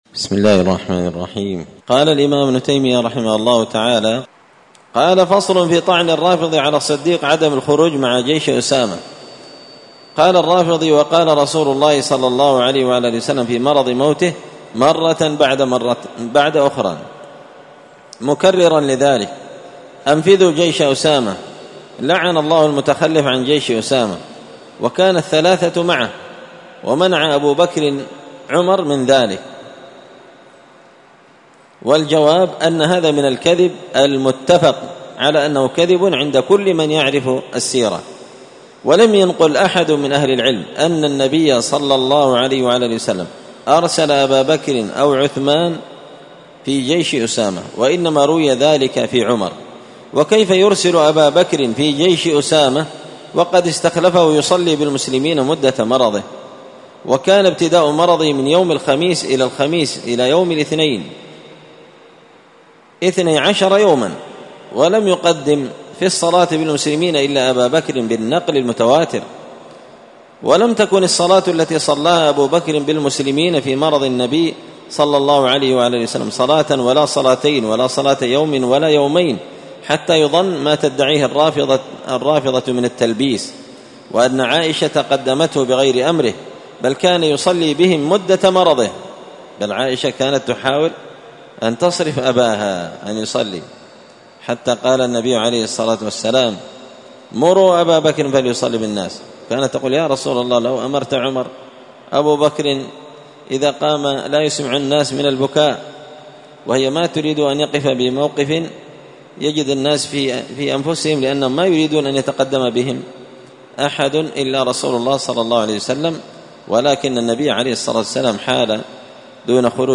الأربعاء 25 ذو القعدة 1444 هــــ | الدروس، دروس الردود، مختصر منهاج السنة النبوية لشيخ الإسلام ابن تيمية | شارك بتعليقك | 11 المشاهدات